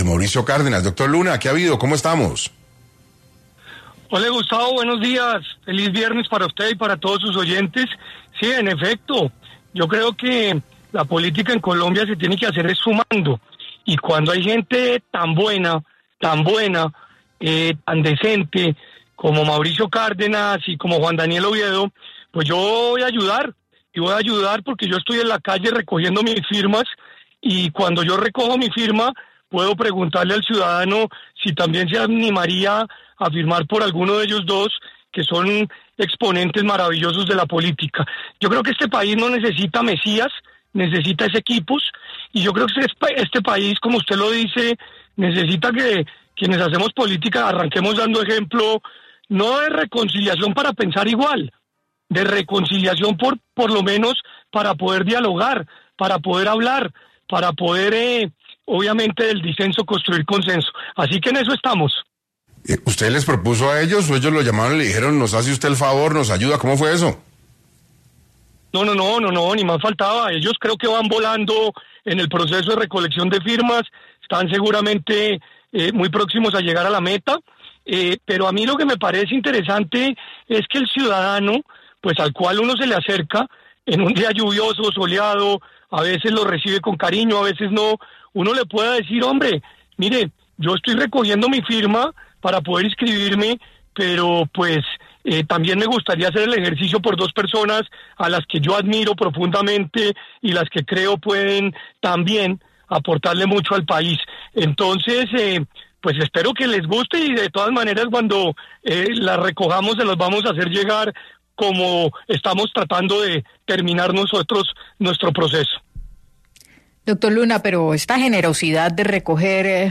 El precandidato David Luna confirmó en 6AM de Caracol Radio que ha decidido que sus equipos de voluntarios, además de recoger sus propias firmas, también recolecten las de Juan Daniel Oviedo y Mauricio Cárdenas para la contienda electoral de 2026.